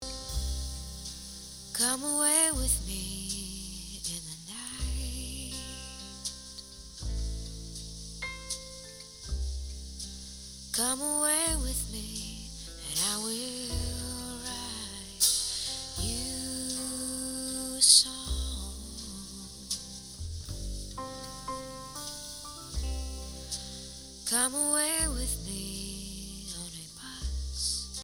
quand je joue l'originale avec phase inversé avec le fichier à 10 conversions, il en sort quand-même encore beaucoup de son => ce qui veut dire que ces deux fichiers ont quand-même pas mal de différences (en dynamique et aussi en bande fréquencielle)